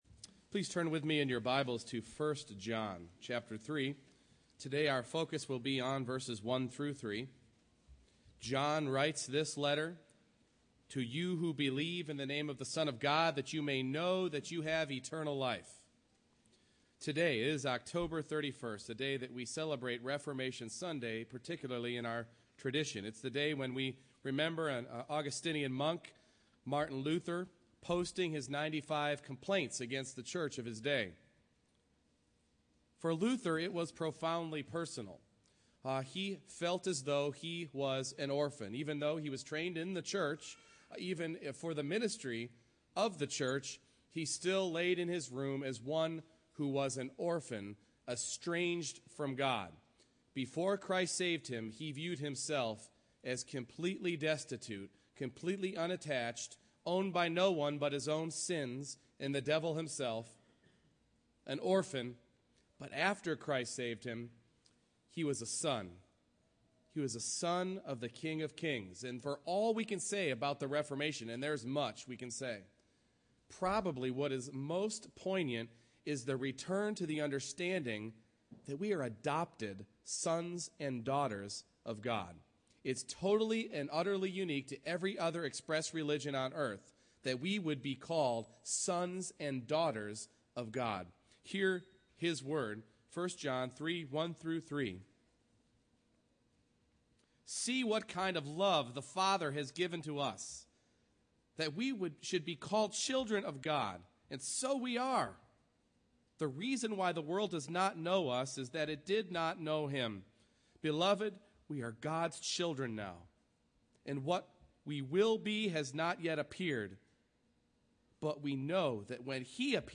1 John 3:1-3 Service Type: Morning Worship Not only forgiven sinners